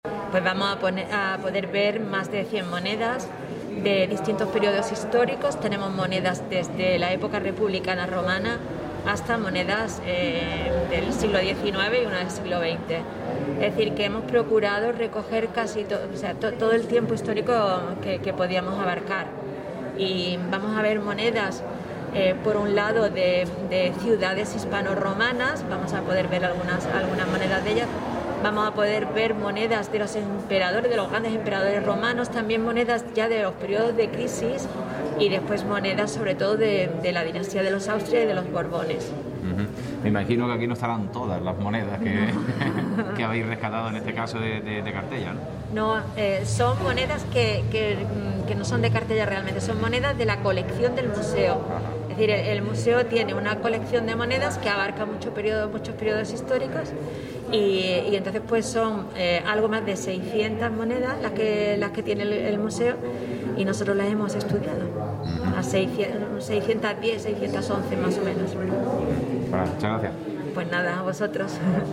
Inaugurada en la galería del Palacio la exposición “Pecvnia”, sobre monedas desde época romana